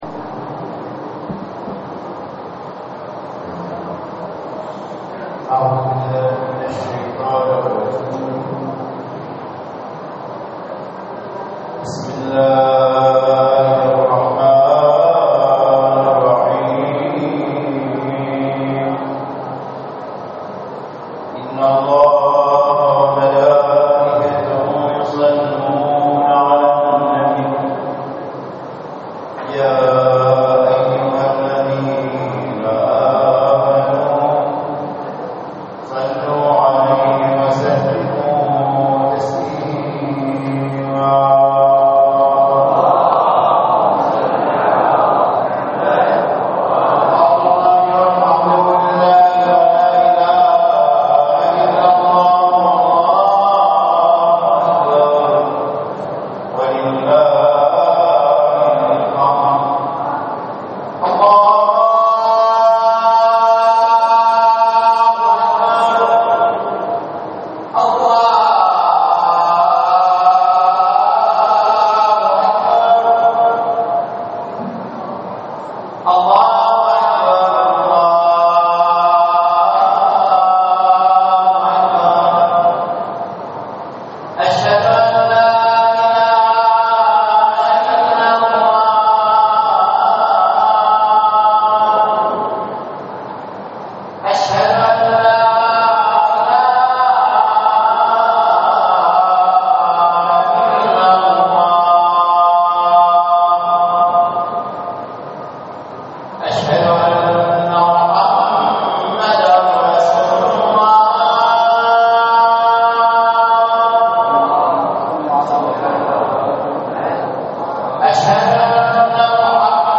صلاة الجمعة في مدينة الناصرية - تقرير صوتي مصور -
للاستماع الى خطبة الجمعة الرجاء اضغط هنا